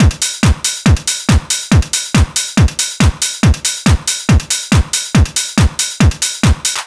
NRG 4 On The Floor 002.wav